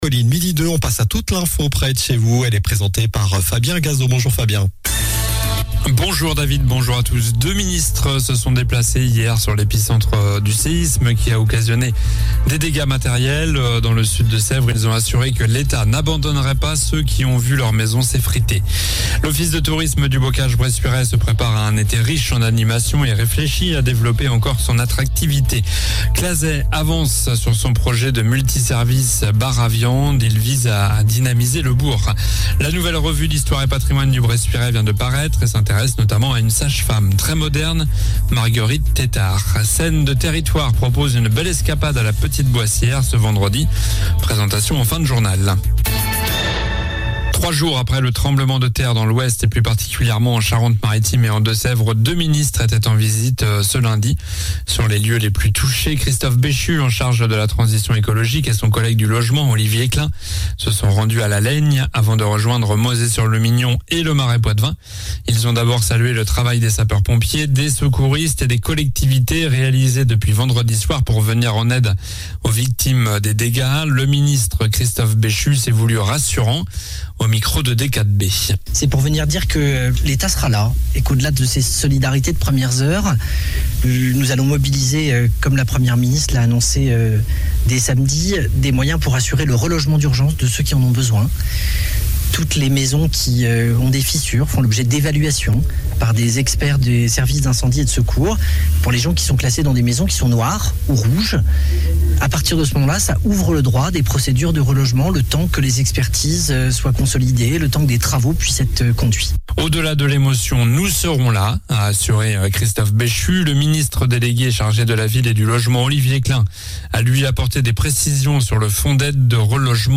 Journal du mardi 20 juin (midi)